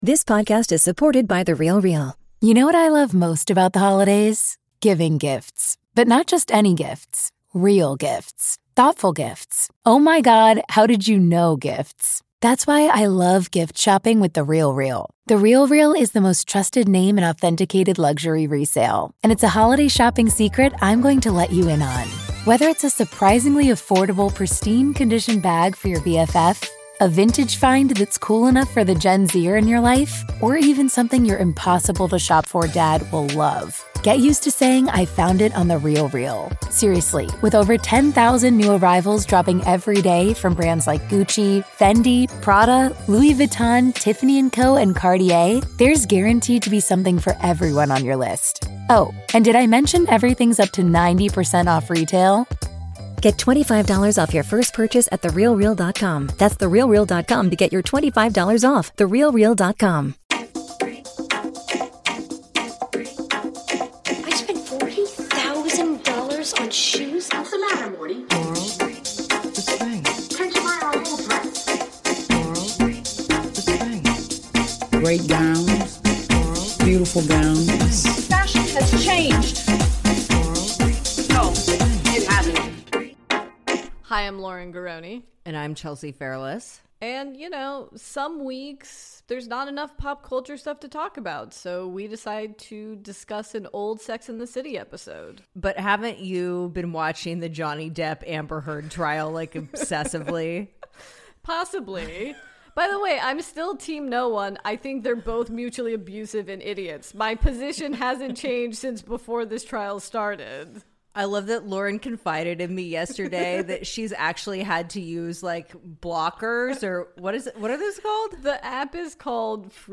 On this week's episode, the ladies discuss the infamous “bi guy” episode. Topics include: the group’s rampant bi-phobia, Miranda’s hated of Steve, Gallerist Charlotte supremacy, Alanis Morissette's cameo, confusing 90’s “queer” rom-coms, Samantha’s Great Lash mascara ensemble, Gen Y erasure, is this a low Manolo episode (?!?!), a call from a Christian listener, SJP's Hollywood Reporter cover, and SO MUCH MORE!